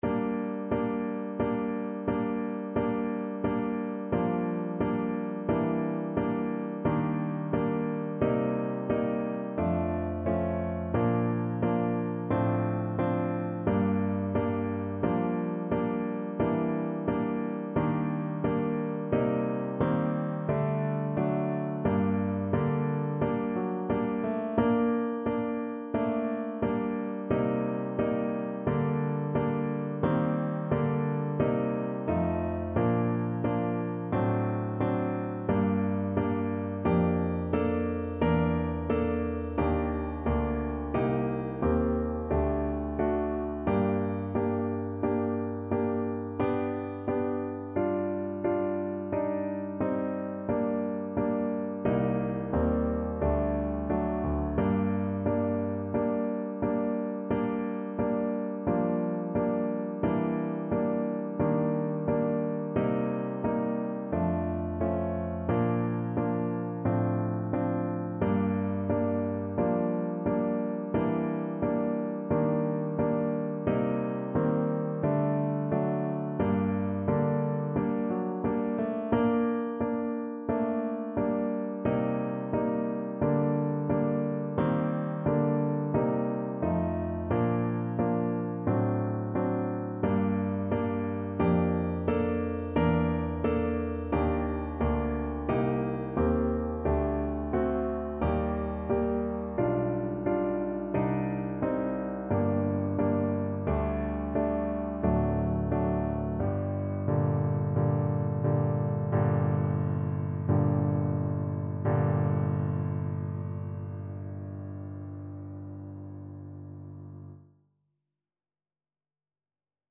Play (or use space bar on your keyboard) Pause Music Playalong - Piano Accompaniment Playalong Band Accompaniment not yet available reset tempo print settings full screen
G minor (Sounding Pitch) A minor (Trumpet in Bb) (View more G minor Music for Trumpet )
Andante =c.88
kojo_no_tsuki_TPT_kar1.mp3